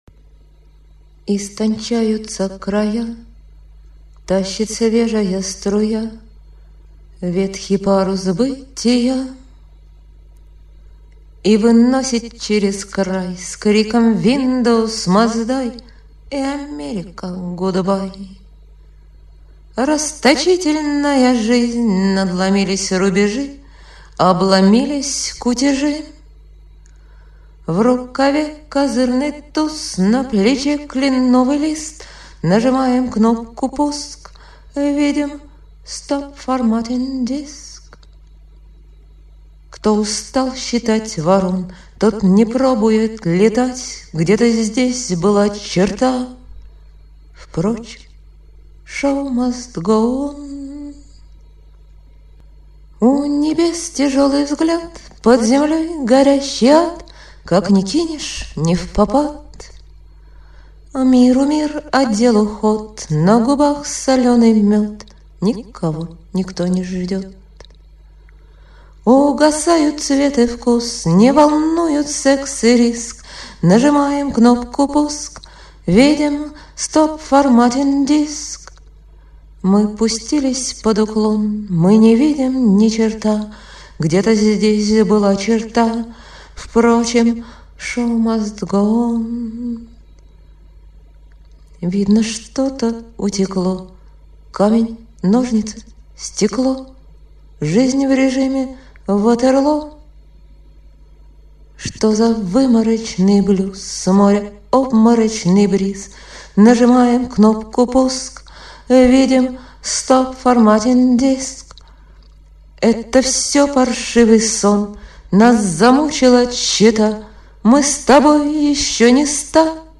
Песенка написалась.
Выморочный блюз (1,07 МБ)
Vymorochny_blues.mp3